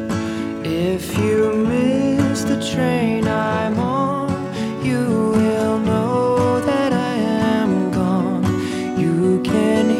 country_en.wav